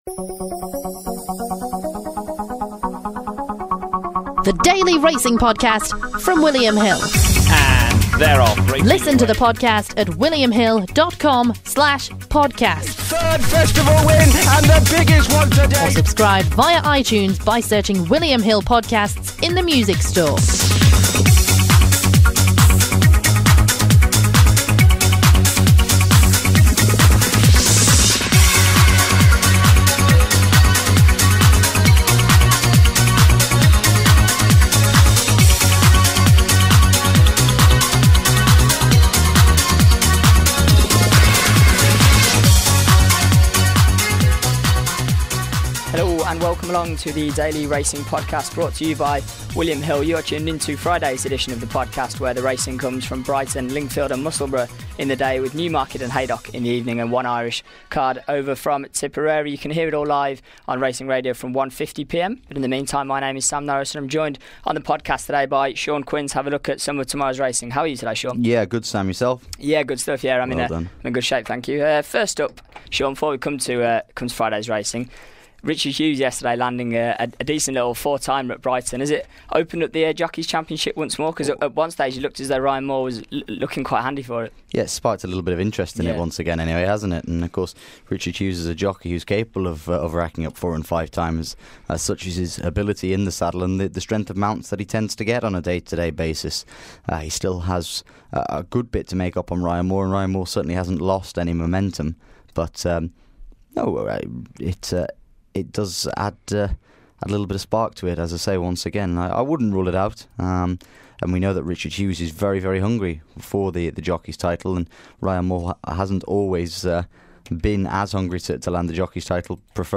in the studio to take a look at three races from Musselburgh on Friday